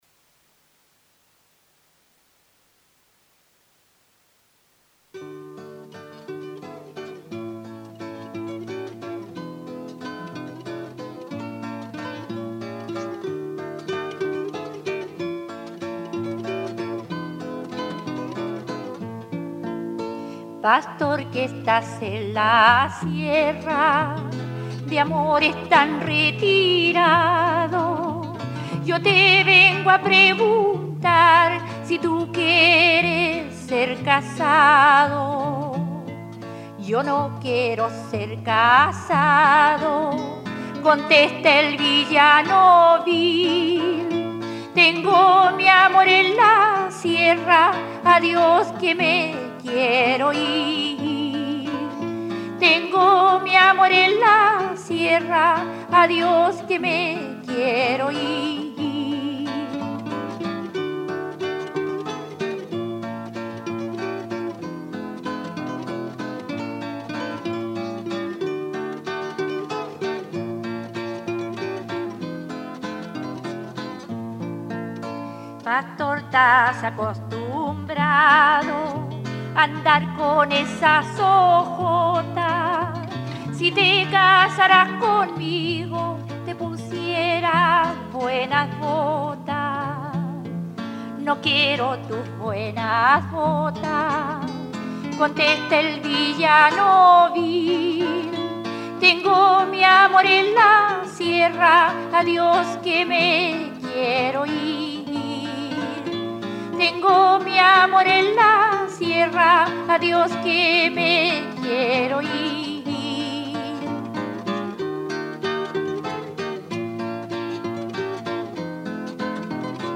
Nacida en Lebu, fue una prolífica investigadora, educadora e intérprete del folclor chileno.
Casete sonoro